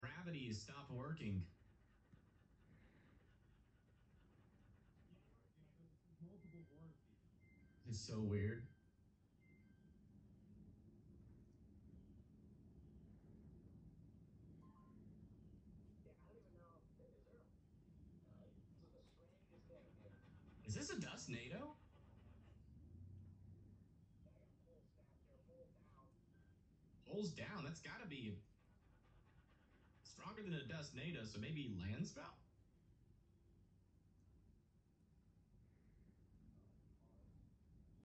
Multiple Big Ass Tornadoes? Sound Effects Free Download